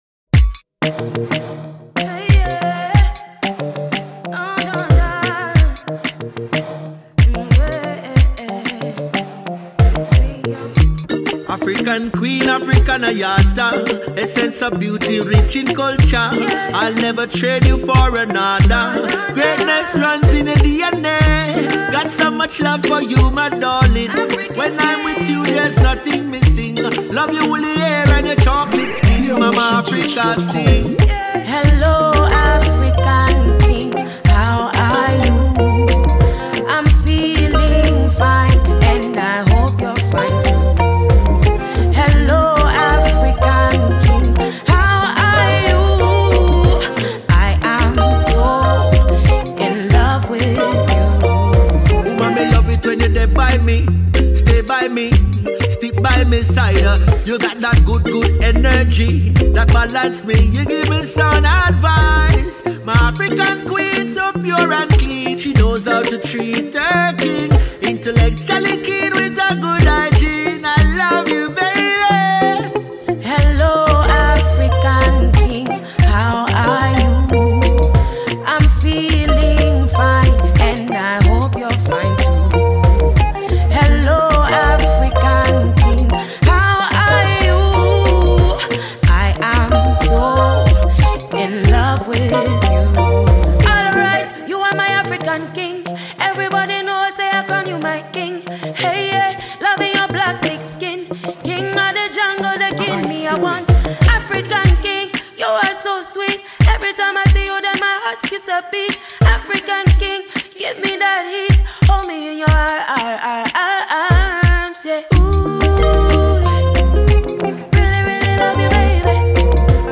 Jamaican Reggae Singer-songwriter